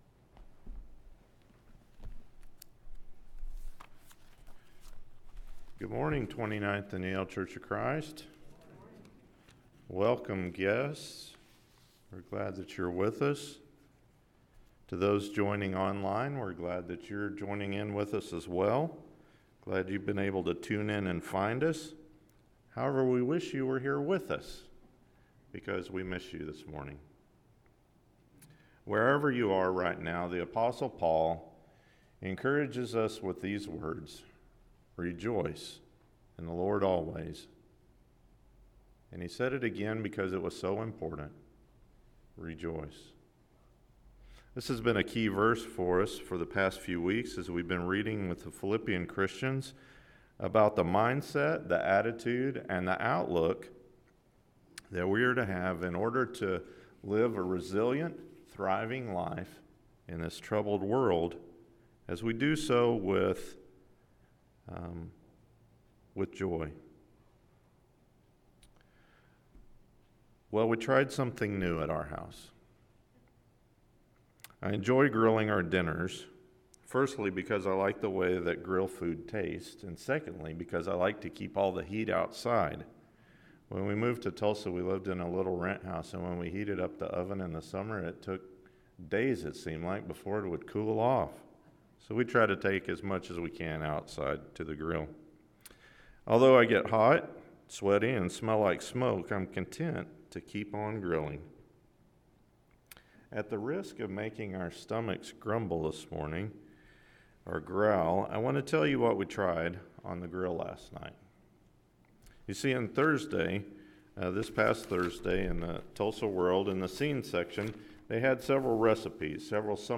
Standing Firm – Philippians 4 – Sermon